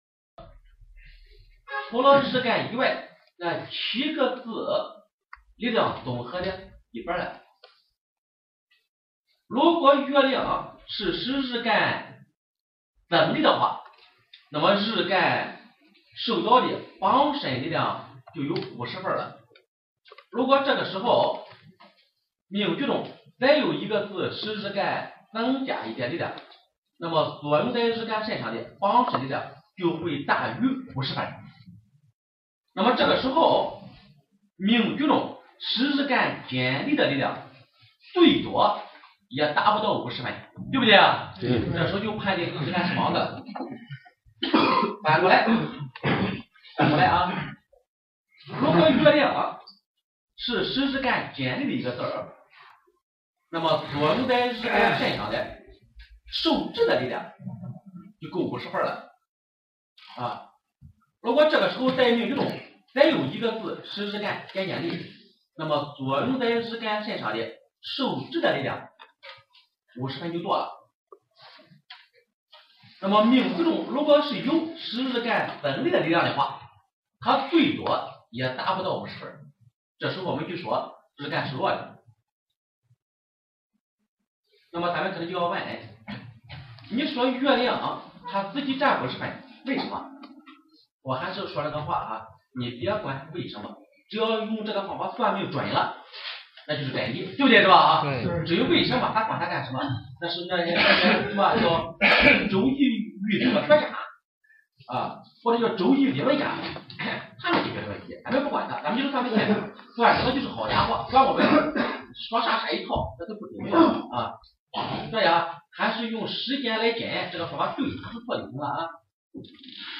声音非常清楚，和以往录音不同，这次的录音非常好，而且都能听清楚。公开了核心的体系，反常论，请易友注意不是反断论，纯阴和纯阳八字的应事原则，命门理论，空亡论，官灾的应期，结婚的应期。